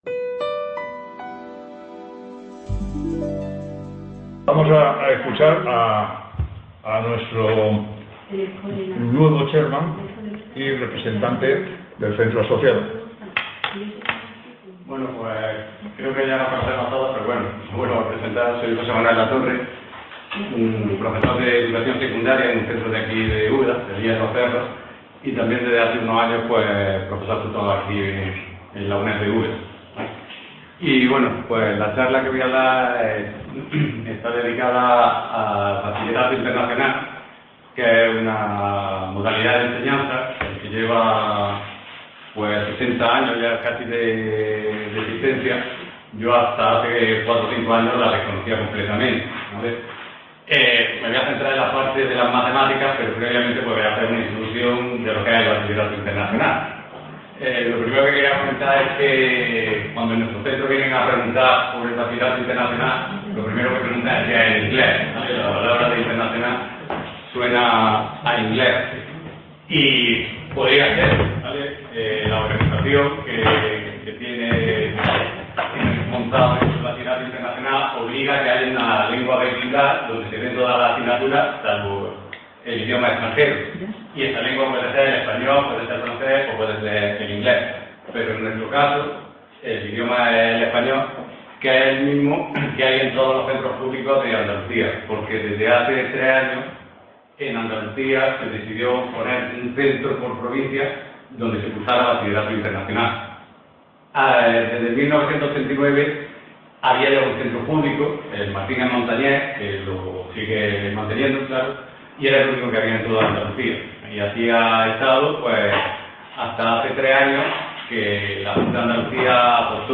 Ponencia S-4ª Las Matemáticas en el Bachillerato…
Sextas Jornadas de Experiencias e Innovación Docente en Estadística y Matemáticas (eXIDO22)